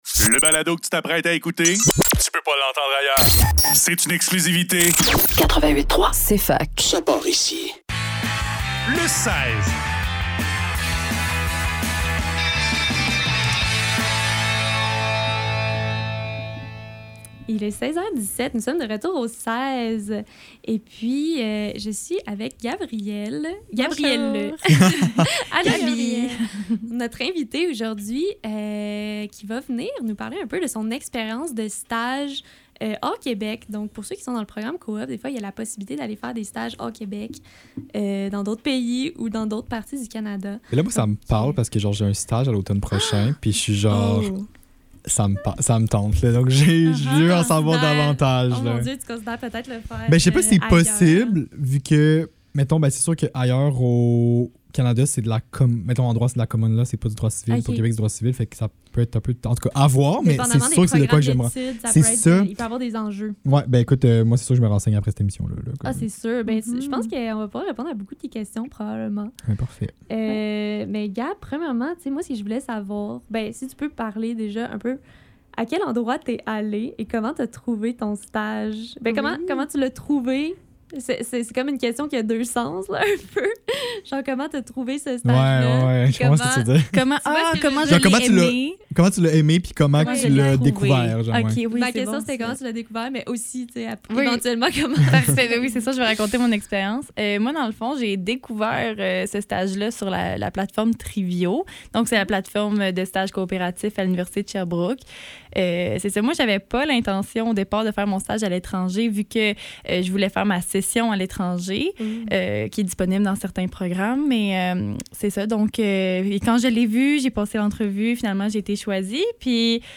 Le seize - Devrais-tu faire un stage hors-Québec ? Entrevue